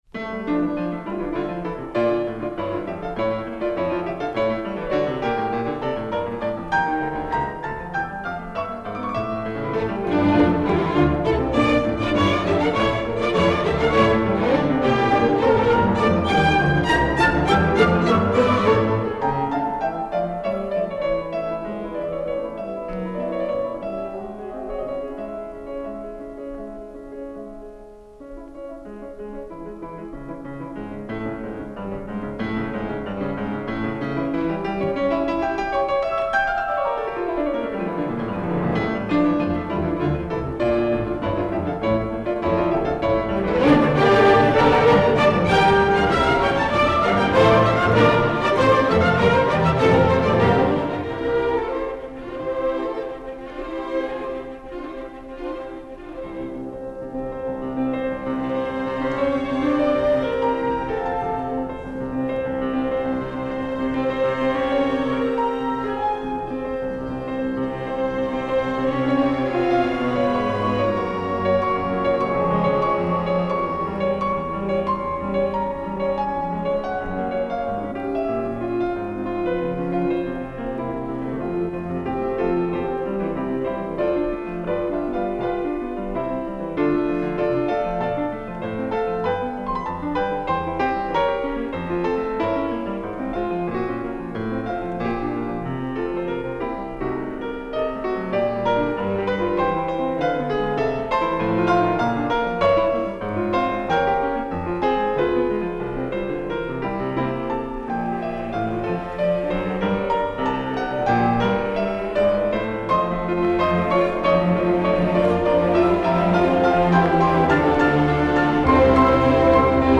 BRAHMS Concerto pour piano n° 1 Rondo Allegro ma non troppo